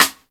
045 - Rim-1.wav